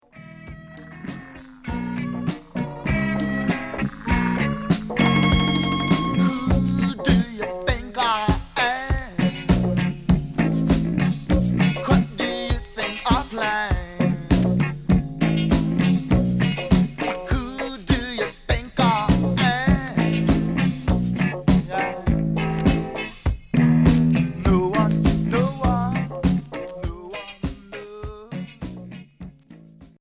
Reggae rock